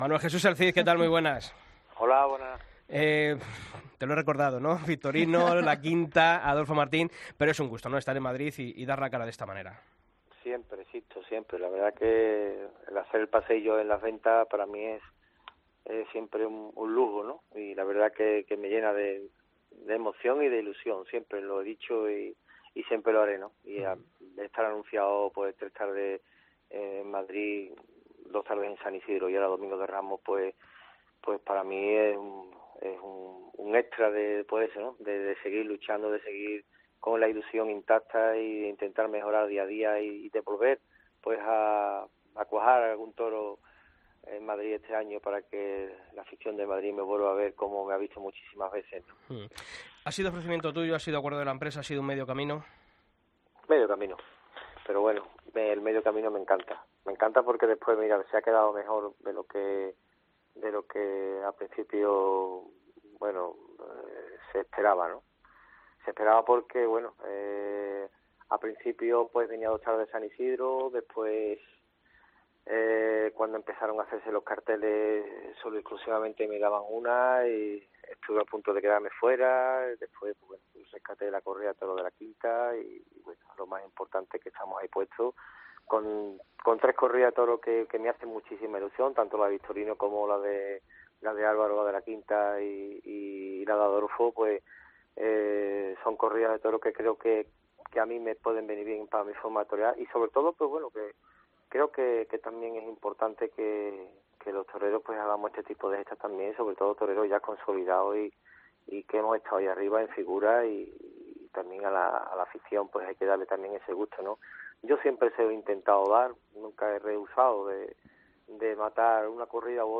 Escucha la entrevista a Manuel Jesús El Cid en El Albero